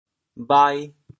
潮州 bhai2 gui2 潮阳 bhai2 gui2 潮州 0 1 潮阳 0 1